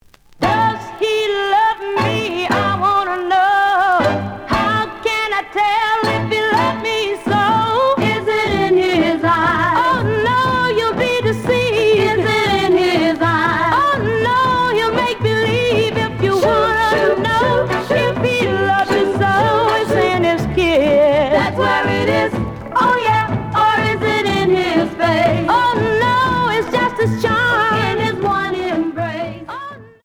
試聴は実際のレコードから録音しています。
●Genre: Rhythm And Blues / Rock 'n' Roll
●Record Grading: VG~VG+